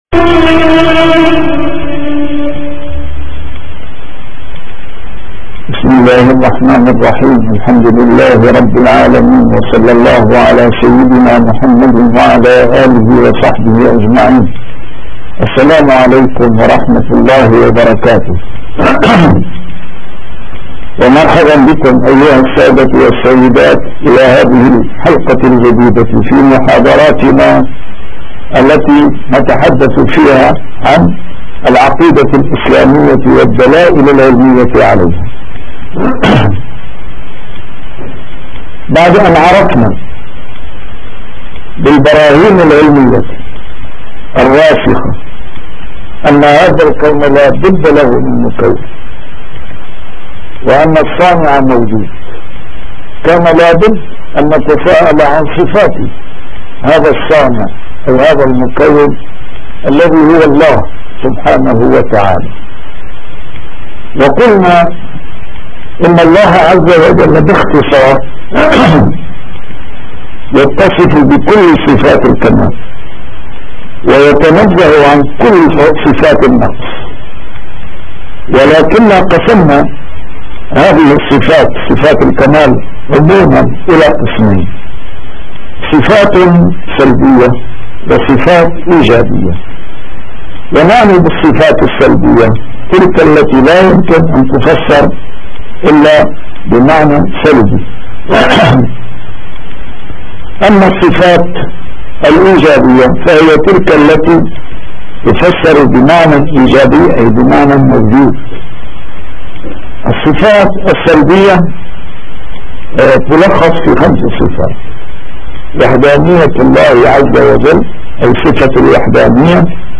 A MARTYR SCHOLAR: IMAM MUHAMMAD SAEED RAMADAN AL-BOUTI - الدروس العلمية - الإسلام في ميزان العلم - 7- الإسلام في ميزان العلم